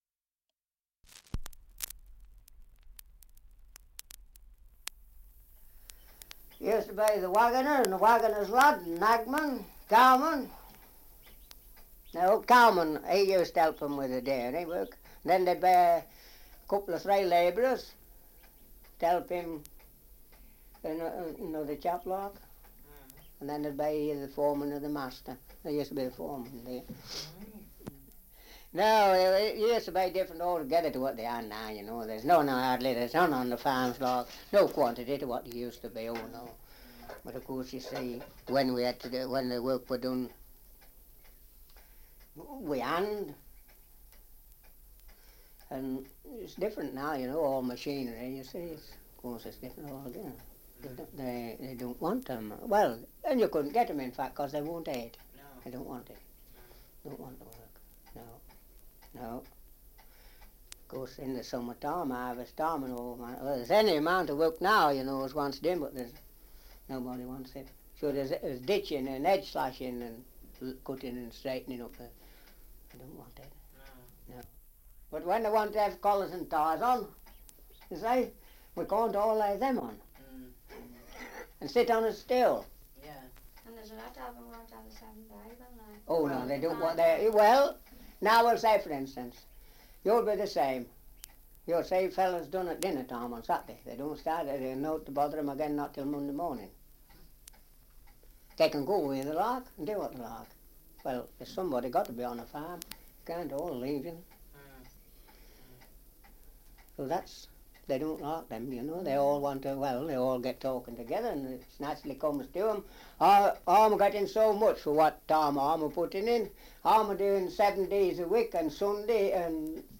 Survey of English Dialects recording in Sheepy Magna, Leicestershire
78 r.p.m., cellulose nitrate on aluminium